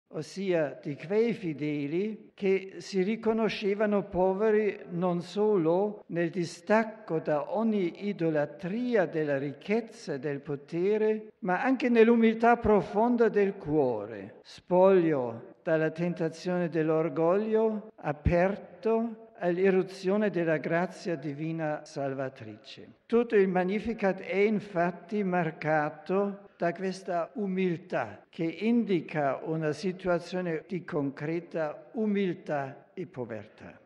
(RV - 15 feb 2006) Cu audienţa generală de miercuri, pe care Benedict XVI a început-o în Bazilica Sfântul Petru plină de pelerini şi a continuat-o apoi în Aula Paul VI din Vatican, Papa a încheiat prin comentarea cântării „Magnificat”, „Sufletul meu îl preamăreşte pe Domnul”, reflecţia asupra Liturgiei Laudelor şi Vesperelor, începută de Ioan Paul II în 2001.